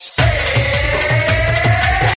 P O L I C E 00:25 < P O L I C E Sound Effect Message 00:05 < Sound Effect Message Alarm Tone 02 00:26 < Alarm Tone 02 wistle 00:02 < wistle Extreme Alarm Clock 00:30 < Extreme Alarm Clock SHOW ALL SIMILAR